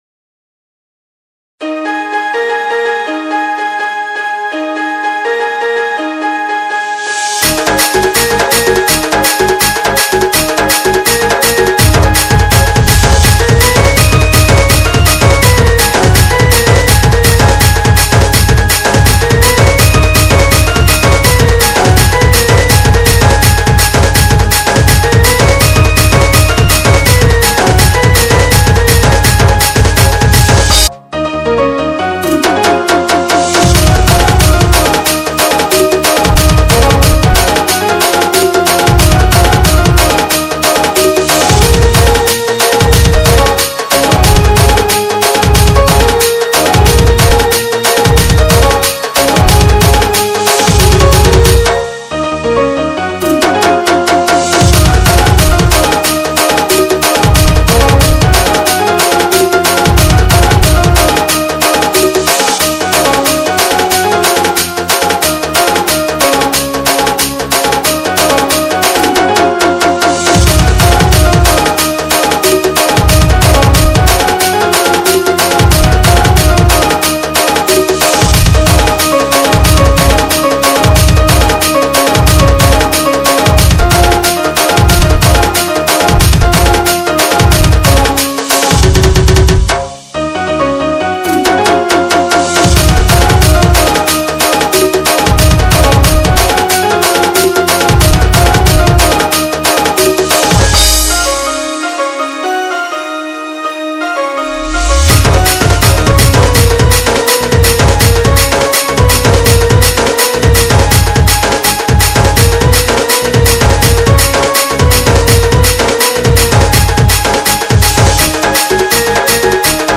SAMBALPURI INSTRUMENT DJ REMIX